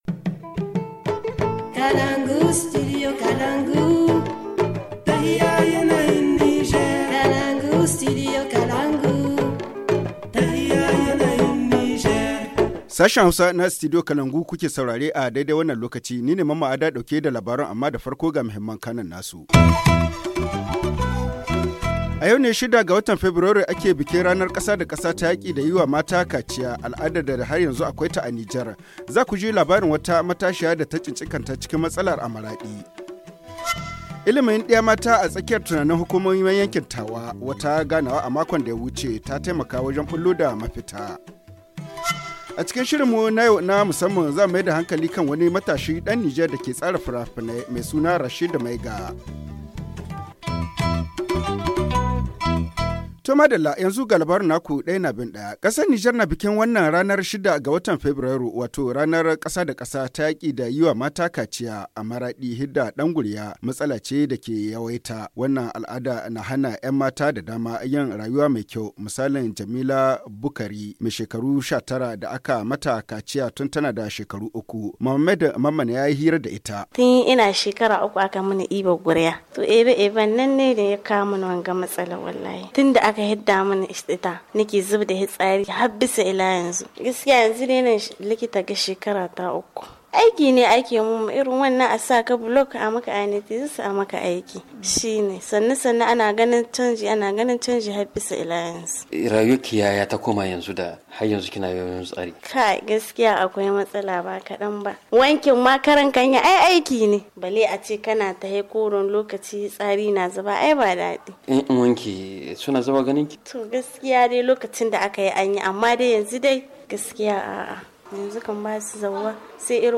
– Santé/ Célébration journée internationale de lutte contre les mutilations génitales féminines. Témoignage, d’une victime.